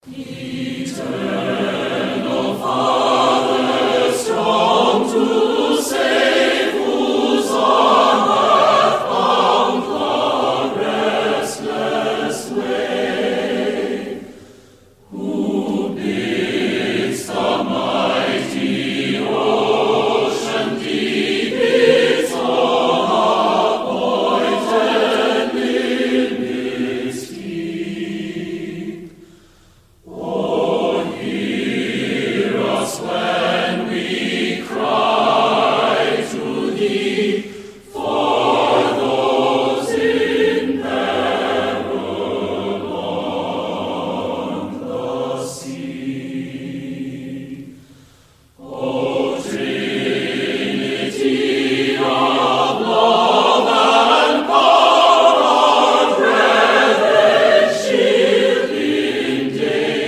Performers: U.S. Navy Chorus